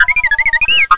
Звуки R2D2
Возмущённый протест